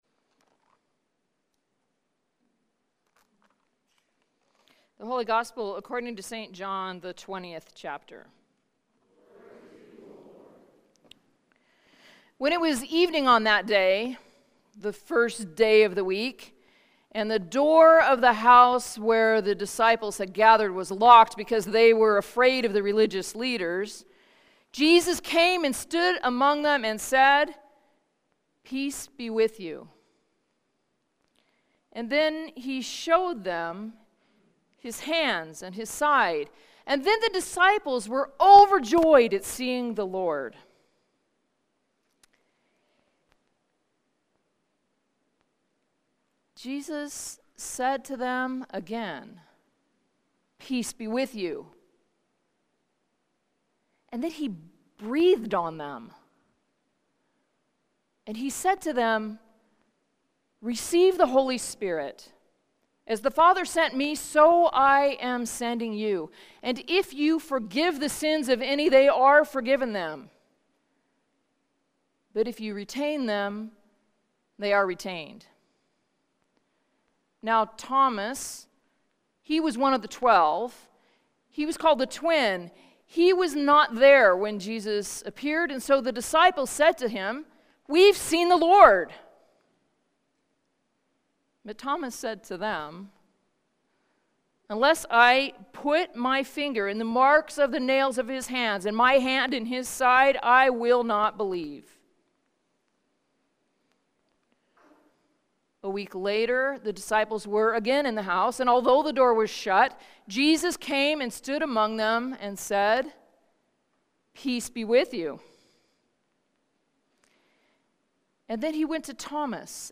Sermons | Church of the Cross